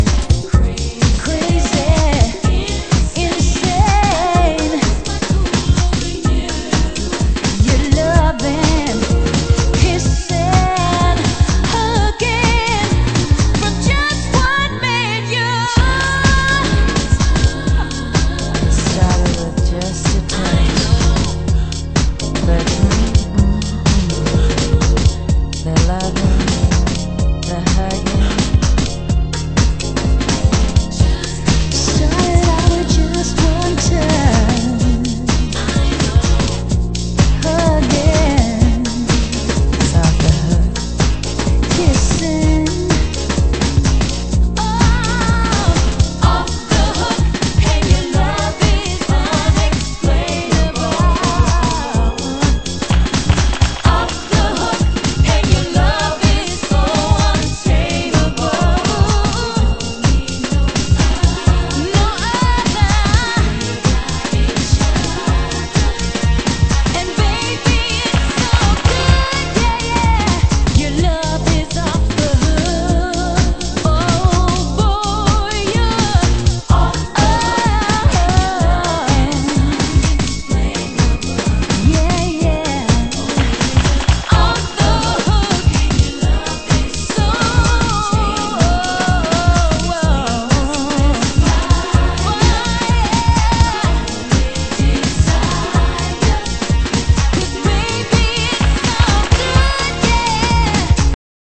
盤質：少しチリパチノイズ有